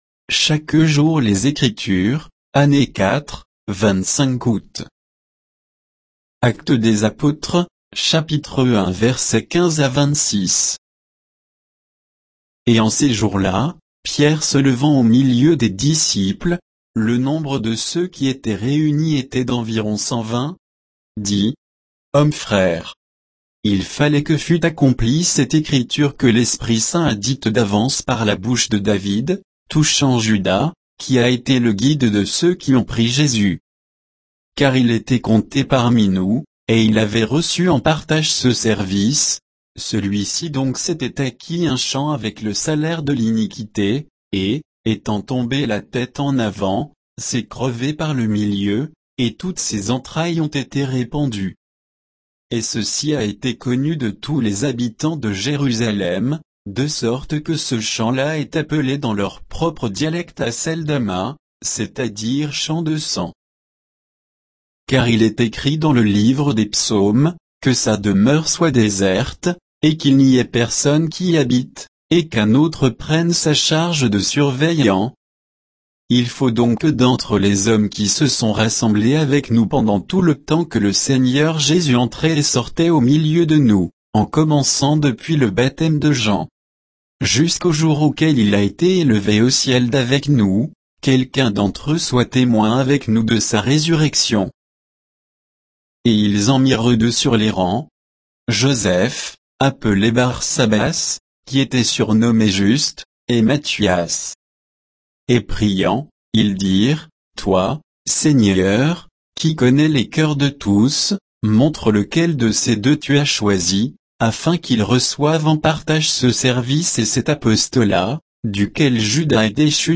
Méditation quoditienne de Chaque jour les Écritures sur Actes 1